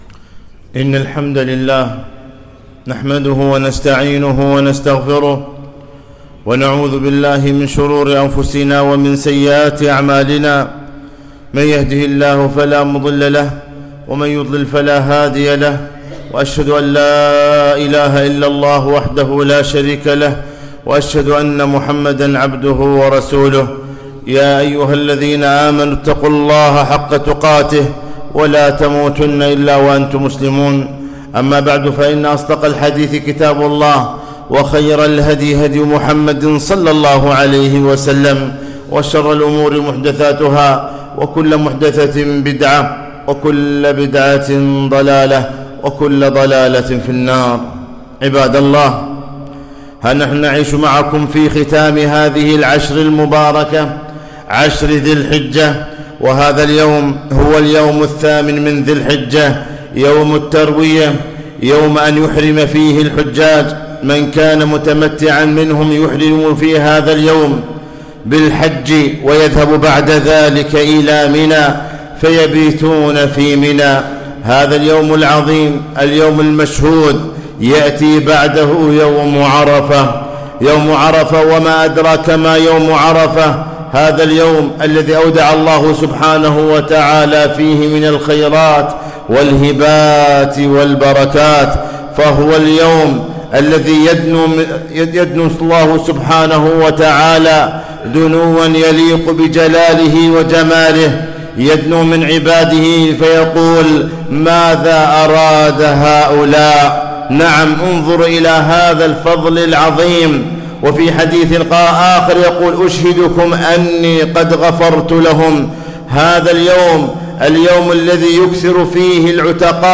خطبة - يوم عرفة